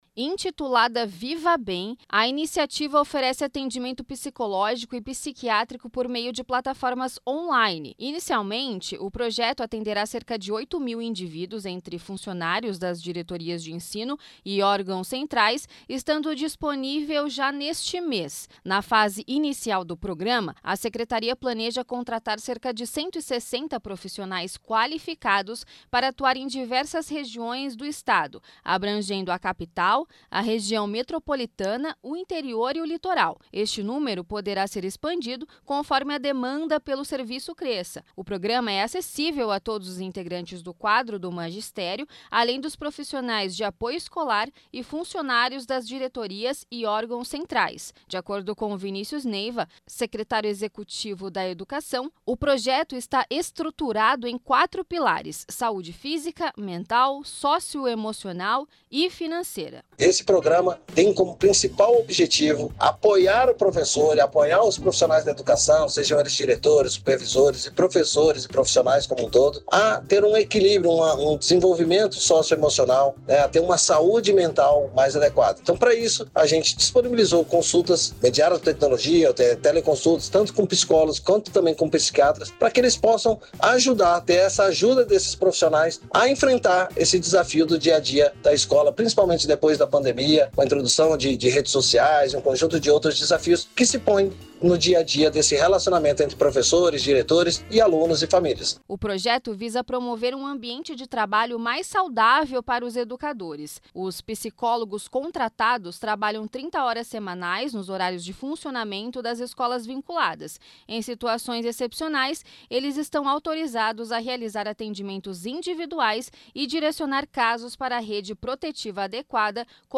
Programa inovador oferece suporte psicológico a mais de 250mil servidores da Educação em São Paulo. Informações com a repórter